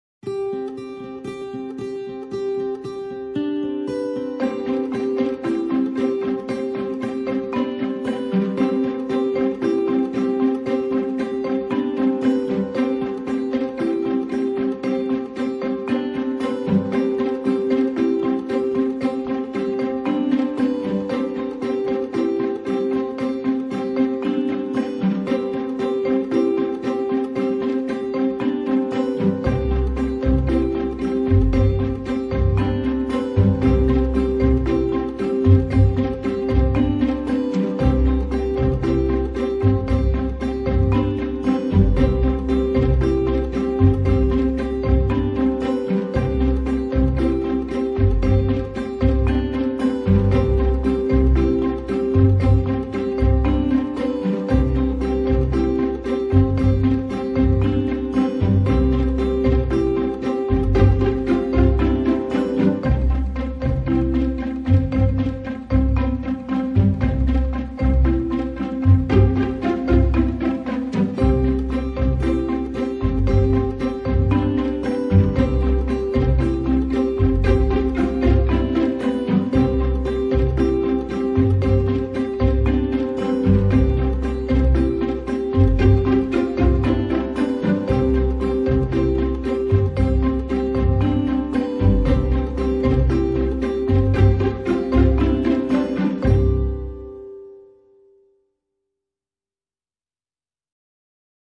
附件2-Loop音乐.mp3